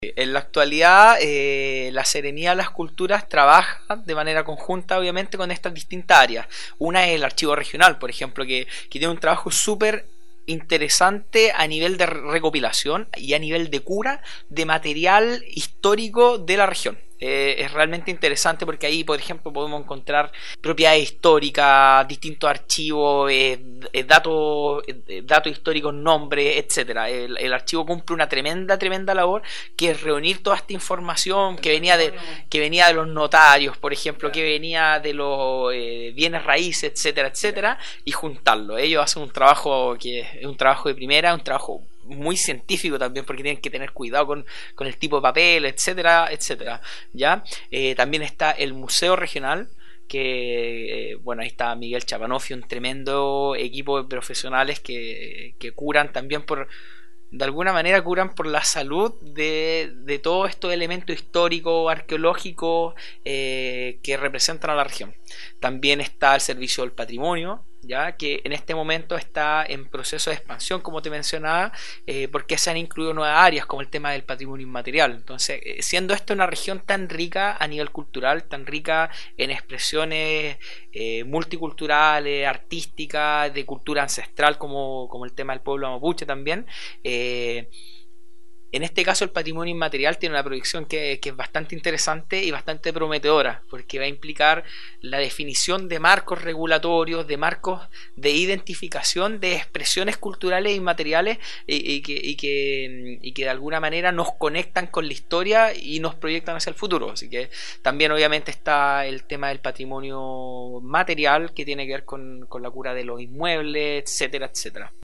Proyecto “El mundo cívico que todos debemos conocer” entrevista al Seremi de las Culturas y las Artes en La Araucanía